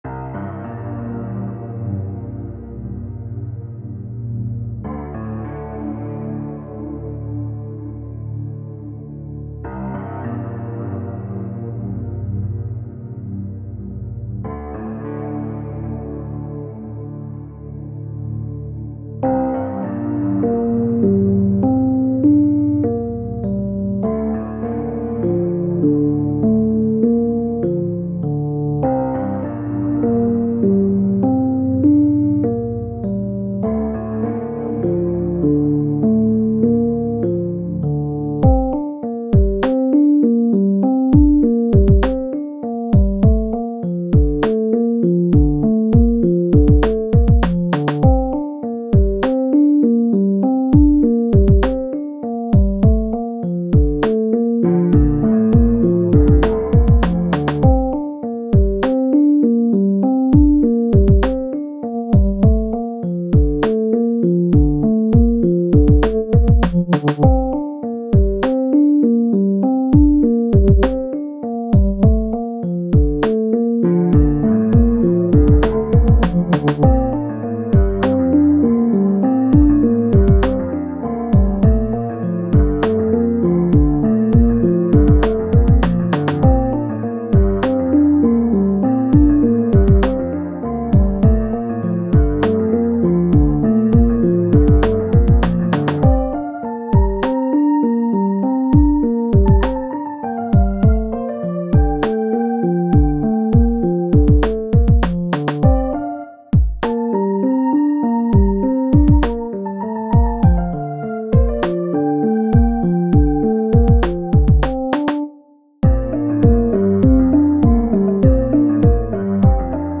I like the percussion
music electronic piano pollen petrichor spring rain 100 bpm haiku